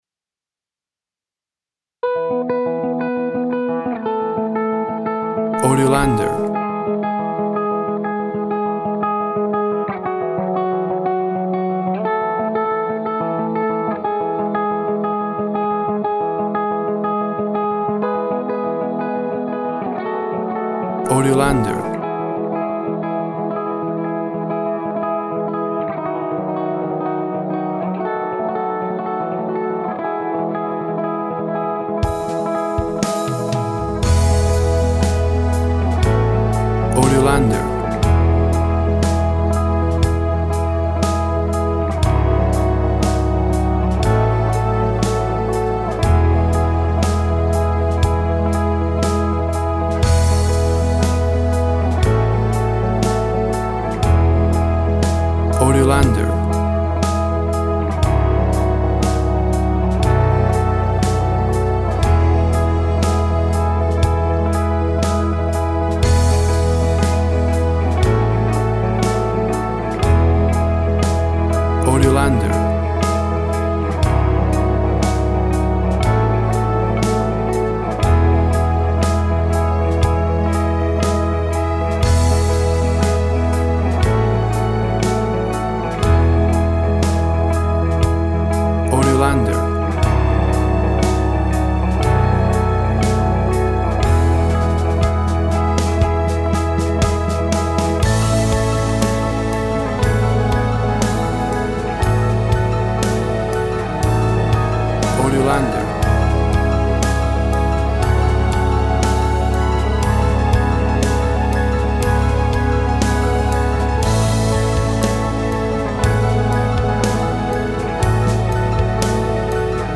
A raw and honest post rock track with orchestral elements.
Tempo (BPM) 120